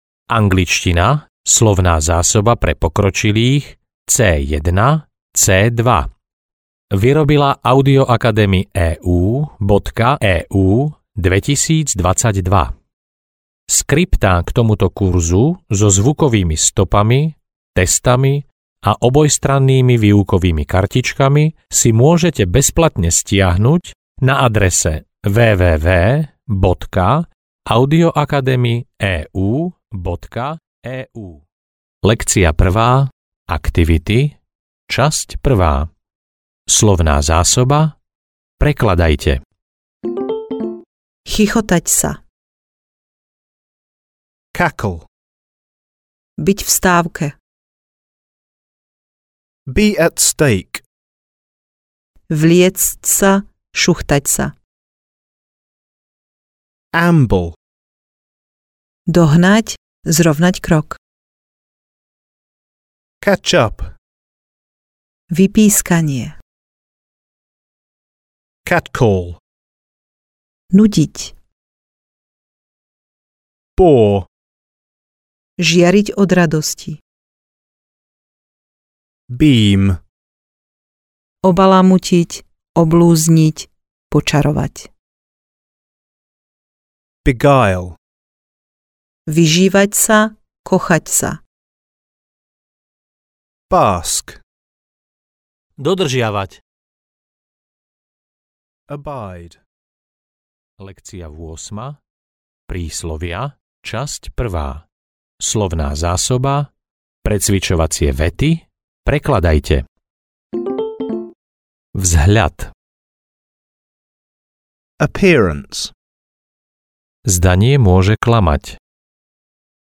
Angličtina pre pokročilých C1, C2 audiokniha
Ukázka z knihy
Potom nasleduje slovíčko a príkladová veta, opäť v oboch možnostiach prekladu (stopy 3 a 4).